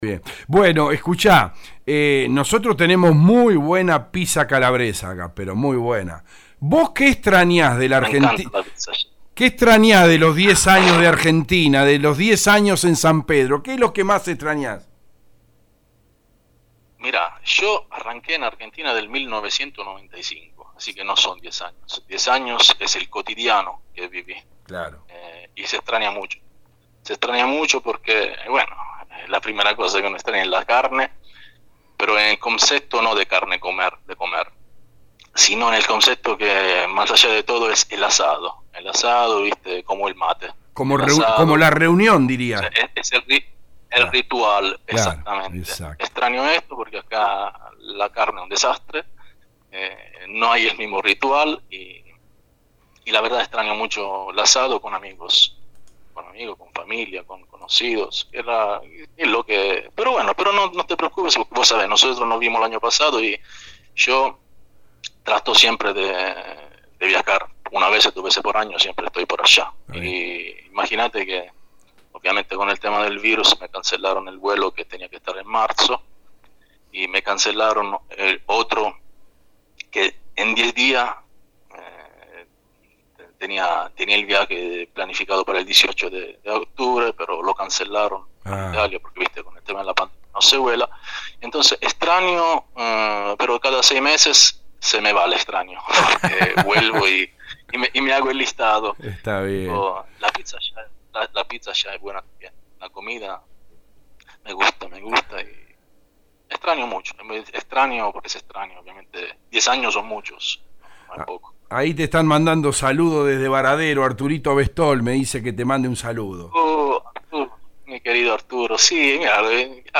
(Nota radial del 8/10/20)